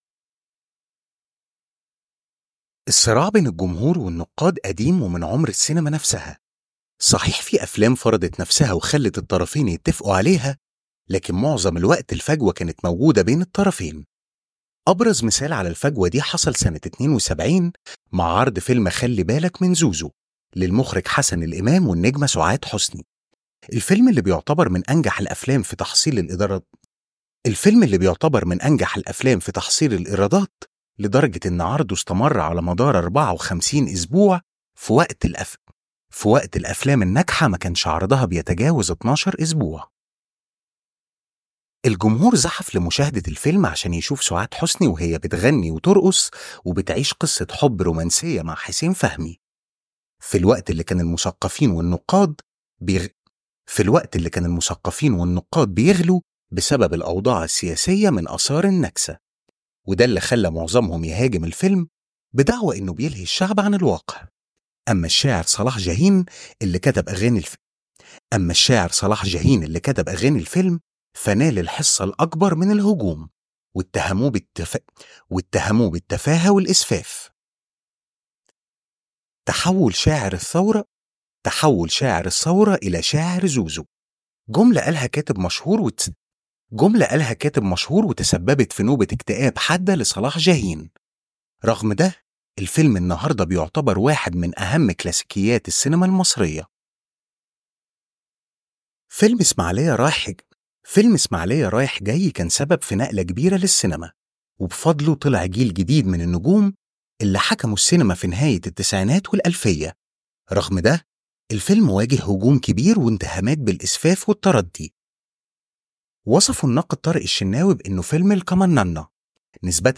Male
Adult (30-50)
Television Spots
Egyptian Arabic Vo